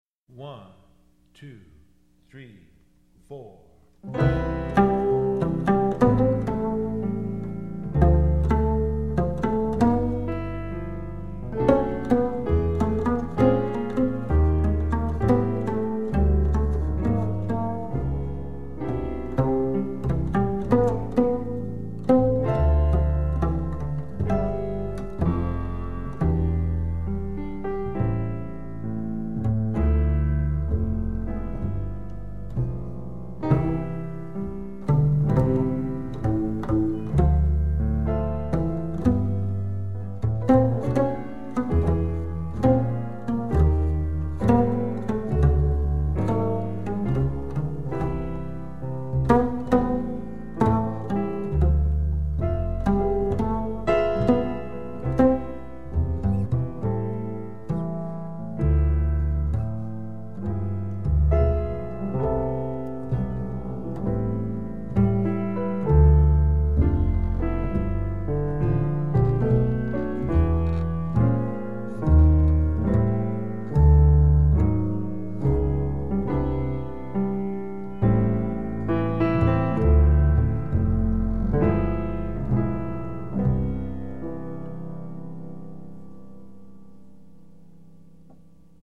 Voicing: Bass